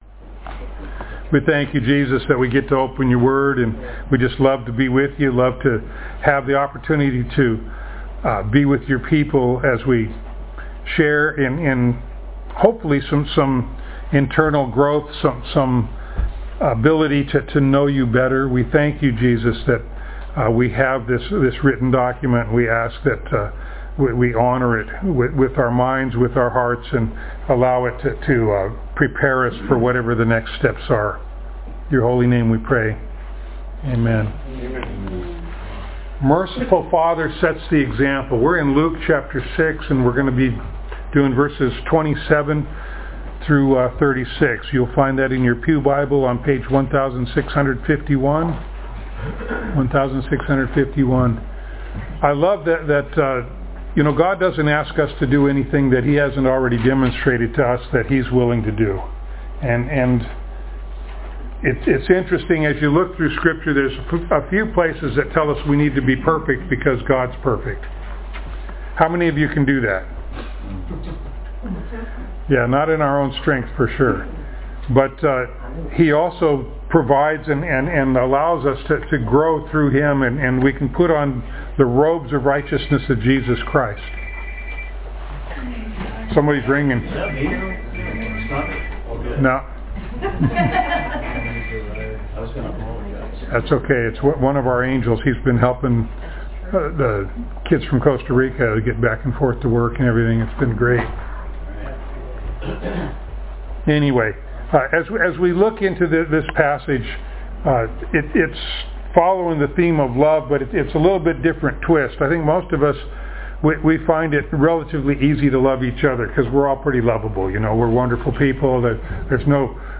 Luke Passage: Luke 6:27-36, Proverbs 25:21-22, 2 Kings 6:8-23, Romans 12:17-21 Service Type: Sunday Morning